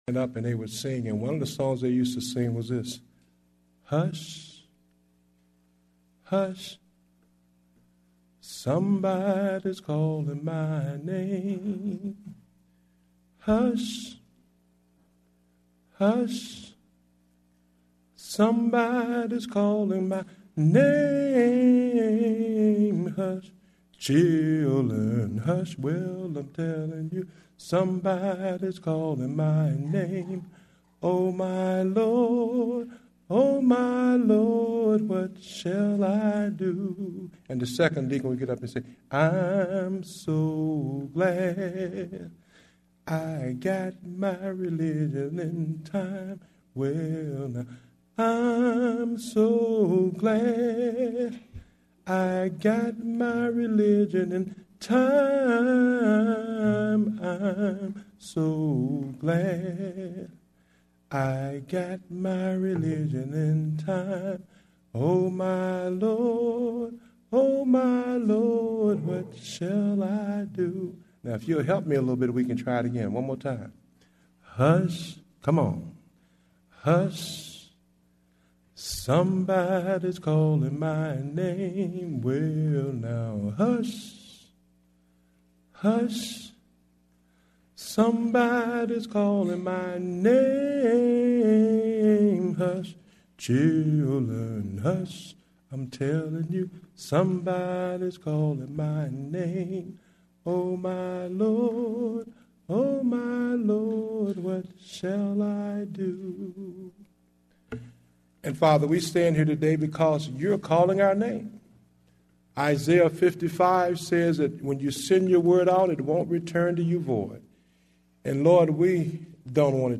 Date: February 14, 2010 (Morning Service)